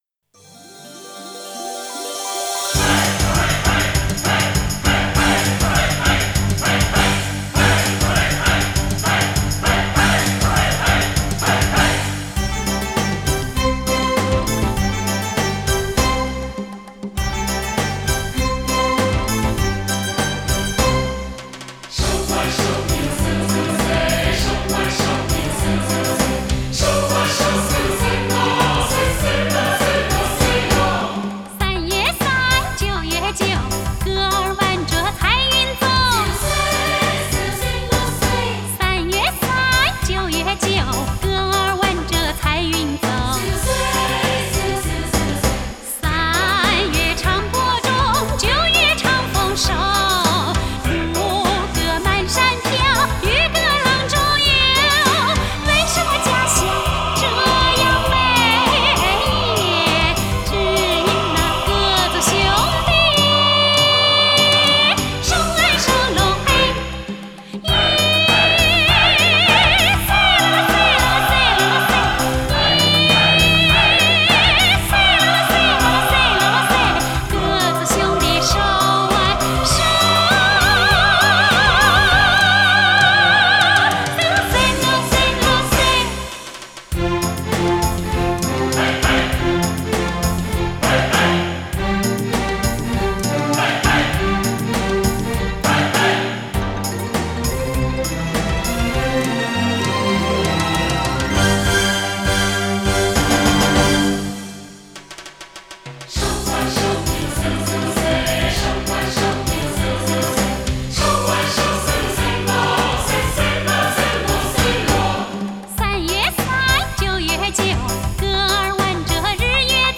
Жанр: Chinese pop / Chinese folk